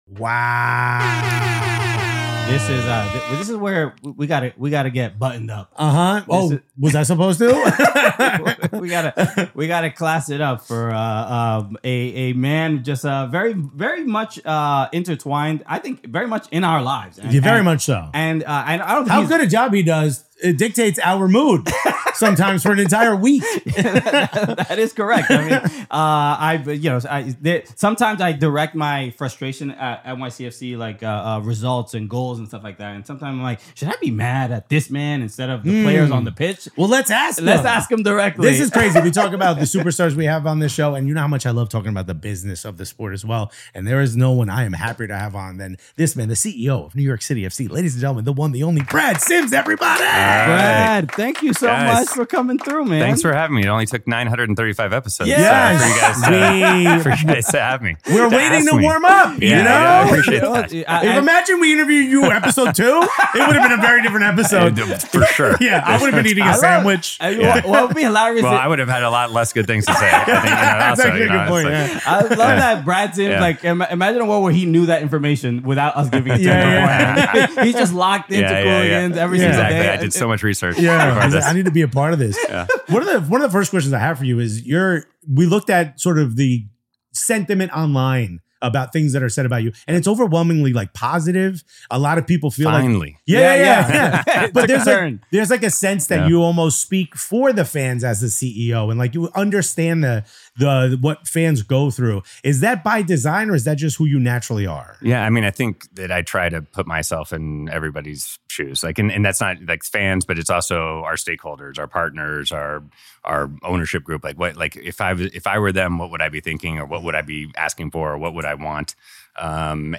Finally, we look ahead at the future of MLS—where the league is headed, what success could look like in the coming years, and how it plans to compete in an increasingly crowded global soccer landscape. It’s an honest, revealing conversation that gives fans a rare look behind the curtain at how decisions are made and where the game is going in the U.S. Timestamps